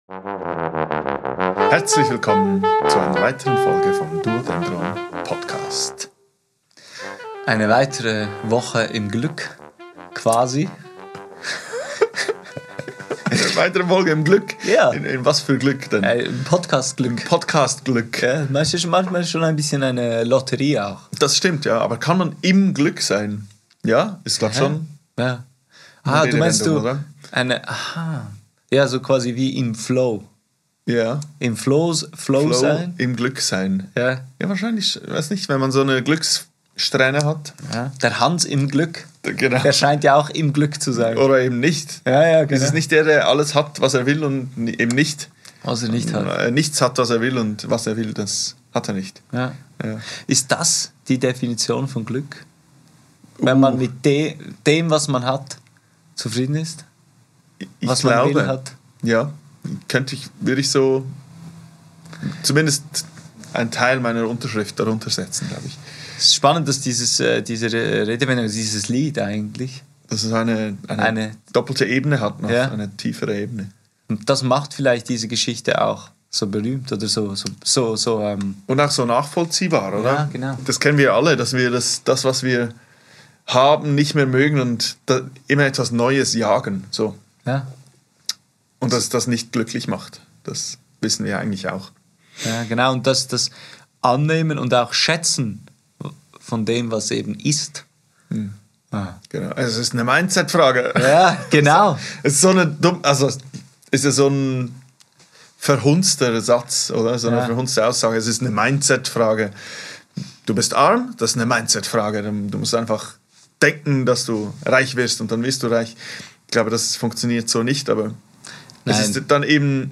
Über Wege zum Glück, guten Kaffee aus Siebträger-Maschinen und was man so aus dem Leben ziehen möchte wird philosophiert. Dann wird aber doch noch etwas Musik gespielt.